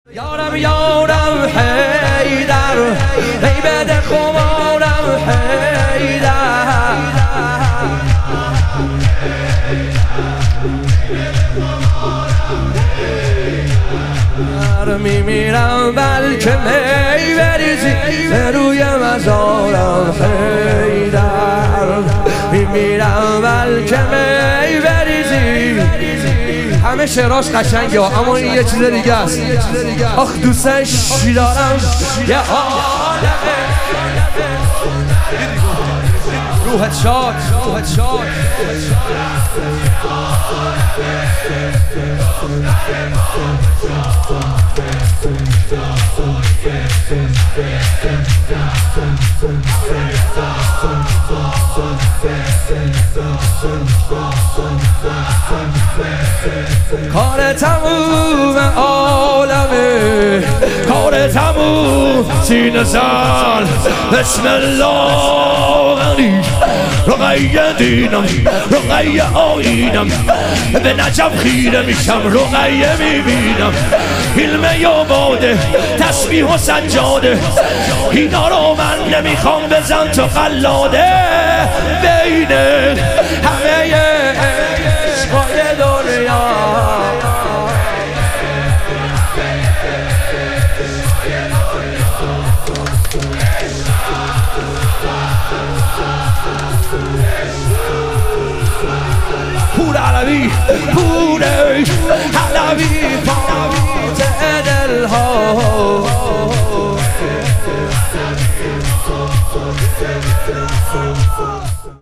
شهادت حضرت زینب کبری علیها سلام - شور